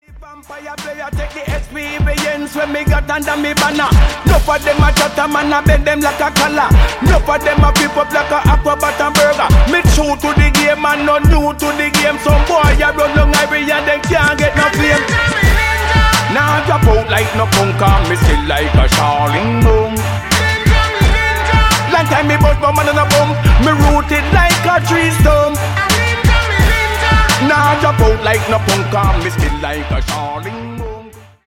This tune is a modern interpretation of a breakthrough digital showcase album by a legendary dancehall artist.